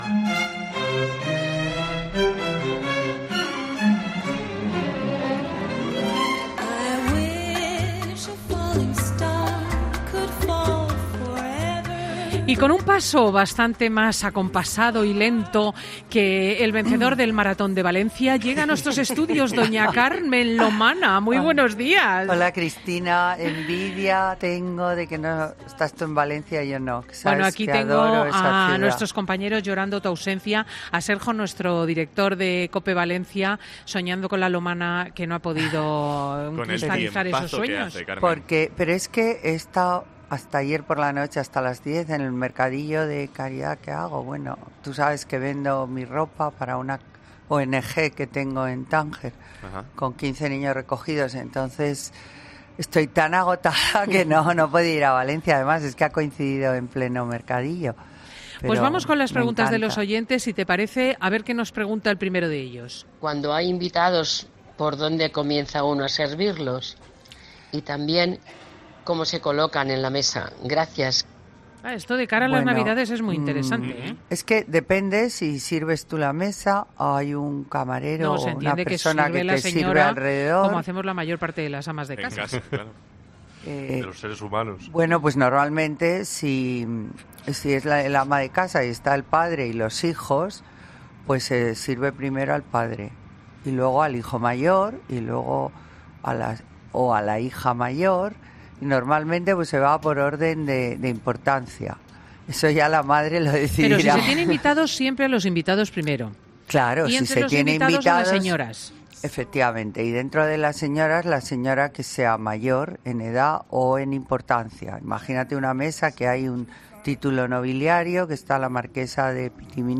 AUDIO: ¿Debemos dejar pasar a una mujer primero? Esta y otras preguntas de oyentes sobre protocolo y saber estar las responde Carmen Lomana